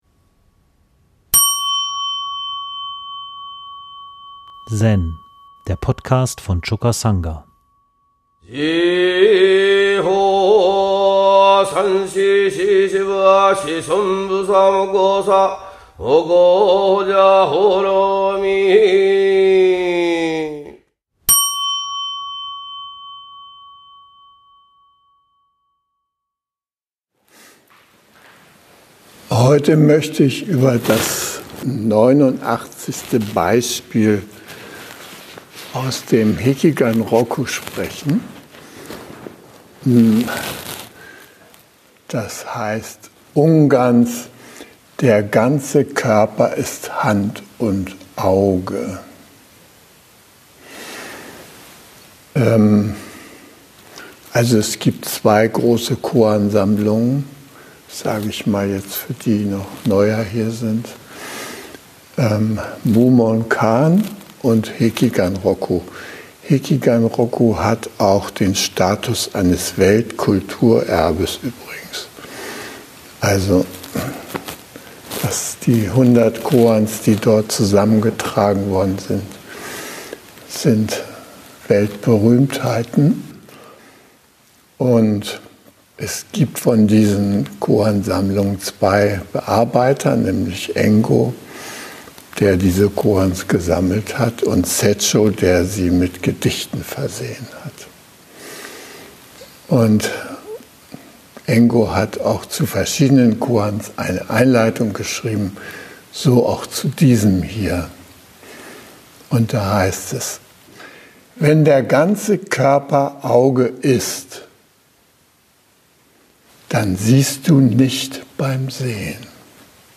Teisho